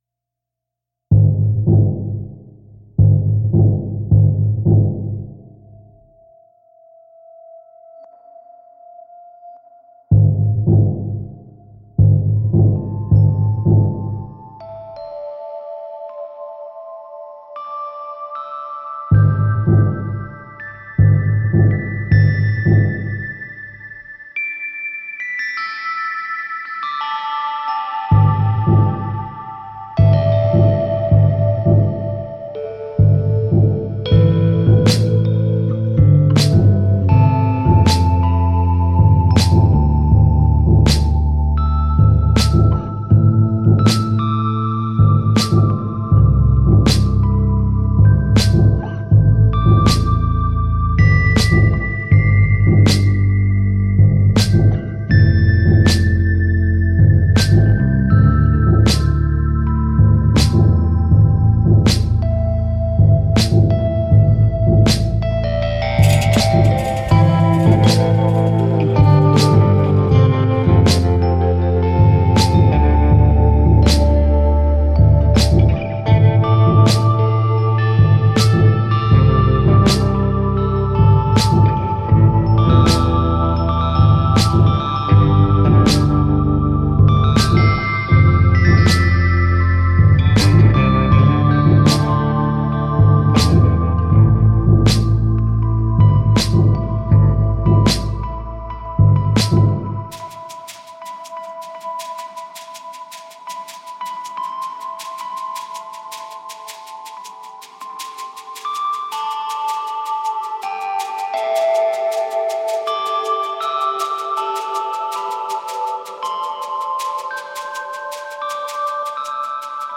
dark electronic instrumentals